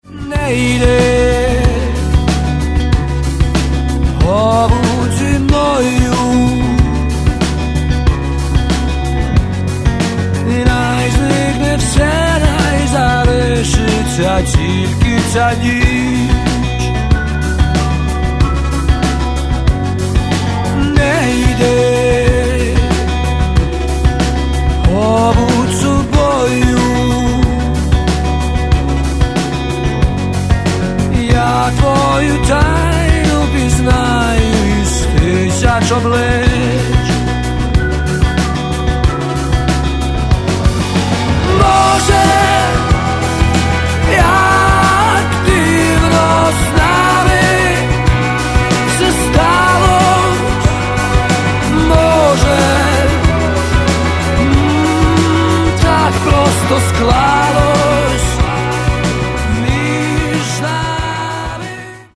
Каталог -> Рок и альтернатива -> Просто рок